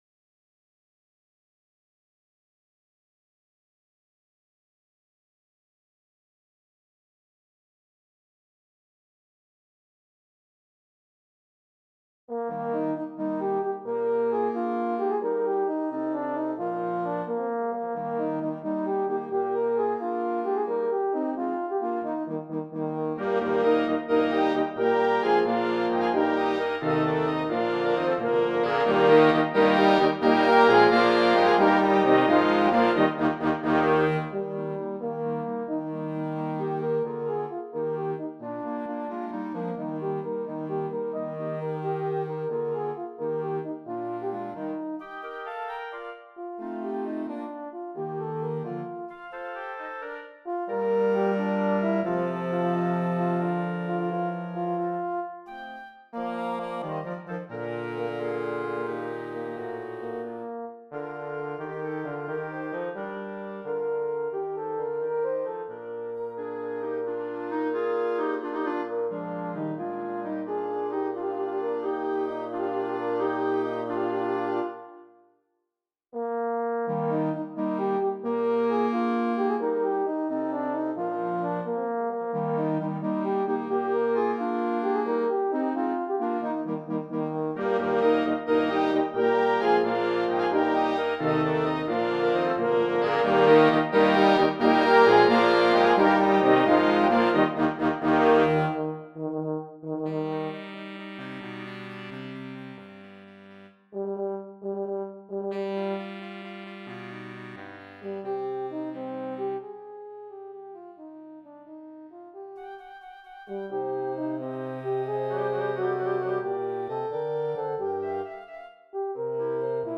Voicing: French Horn w/ Band